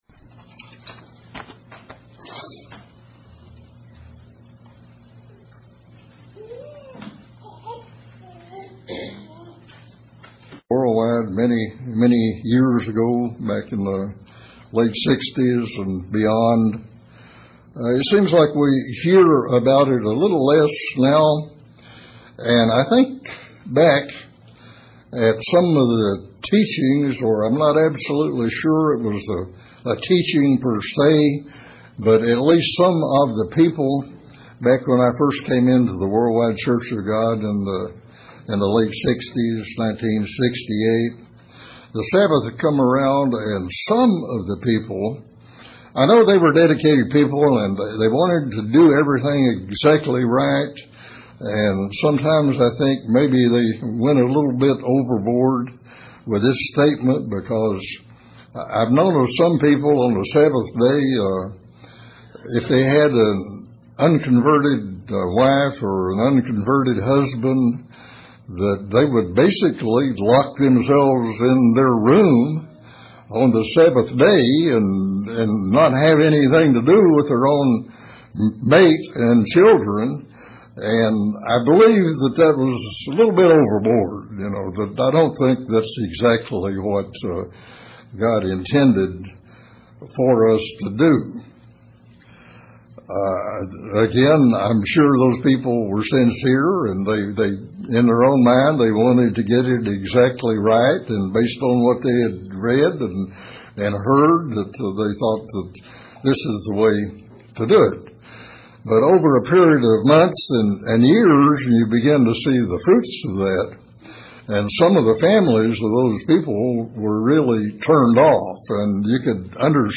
To what extant do "hang around " with them ..This sermon addresses these issues and serves us a solution.....
Given in Paintsville, KY